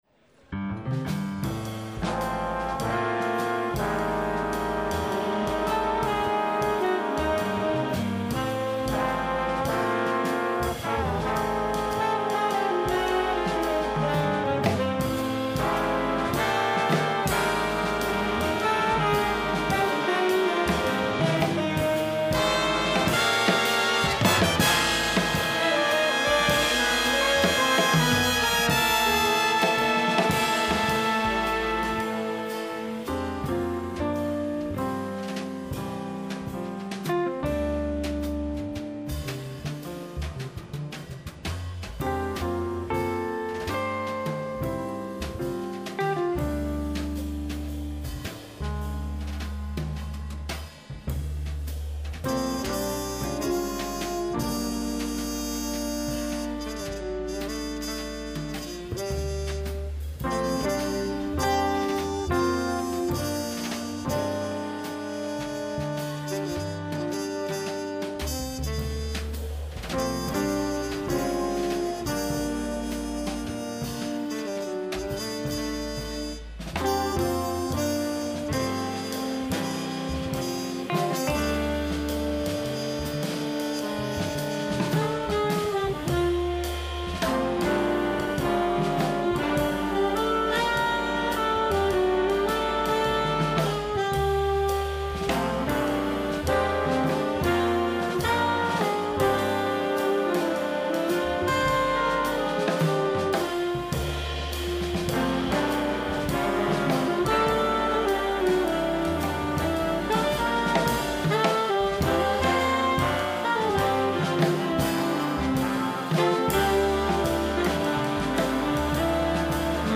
Piano
Guitar
Bass
Drums